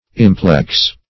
Implex \Im"plex\, a. [L. implexus, p. p. of implectere to